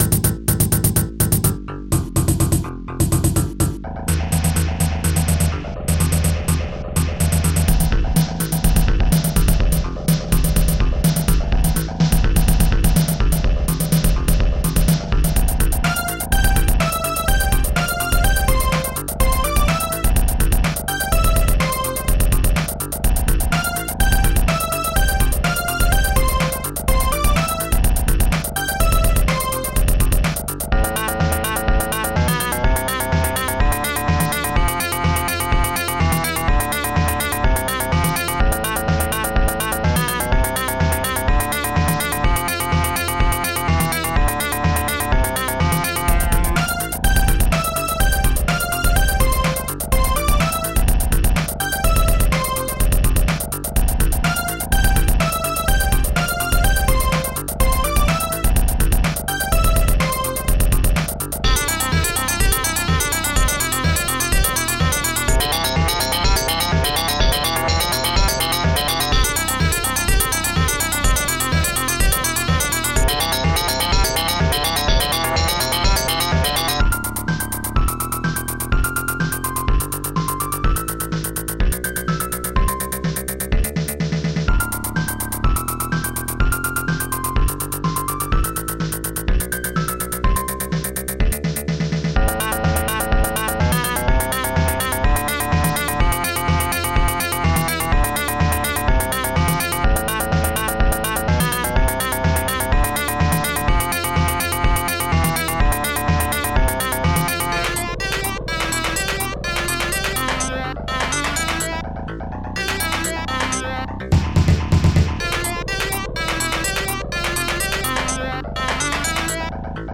Digital Symphony Module
Instruments funkbass bassdrum3 hihat1 snare3 dreambells metalkeys claps1 strings1